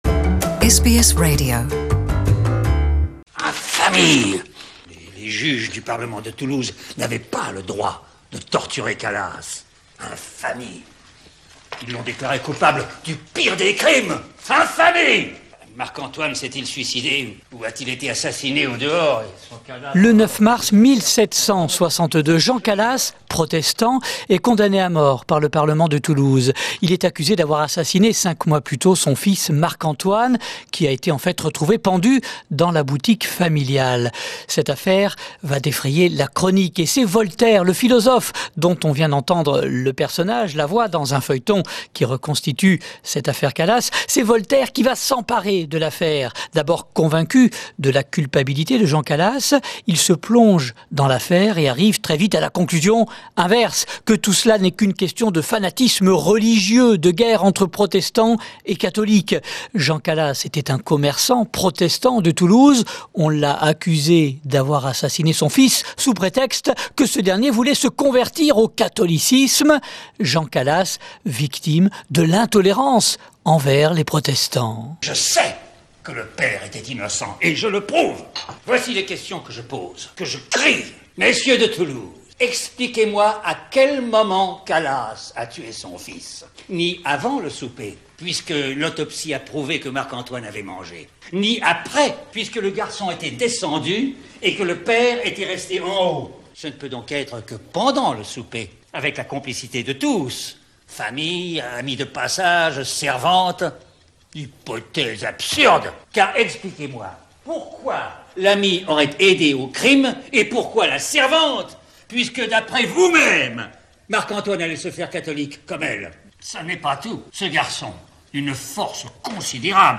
les archives sonores de l'INA, l'Institut National de l'Audiovisuel.